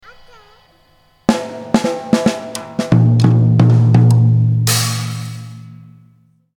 Bubeník